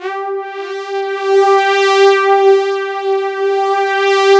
110 PAD G4-R.wav